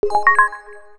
REPORT_INFO.wav